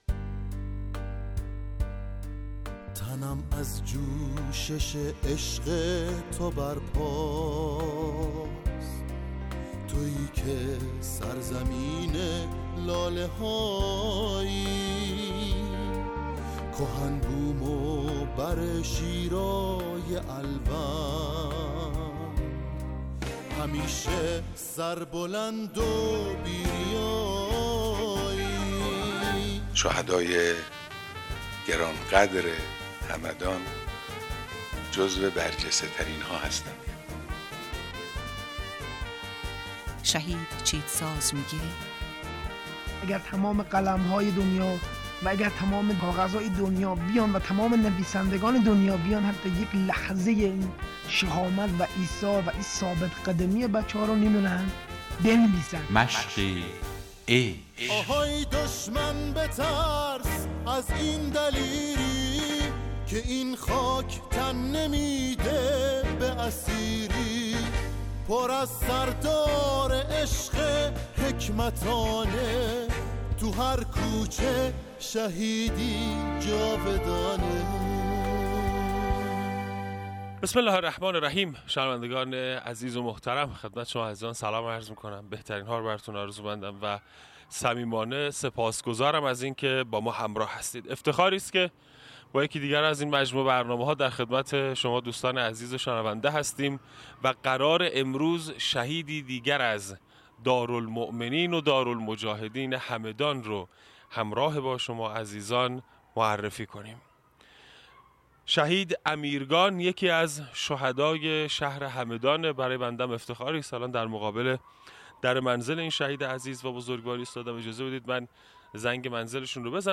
مصاحبه صوتی